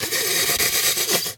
Animal_Impersonations
snake_2_hiss_07.wav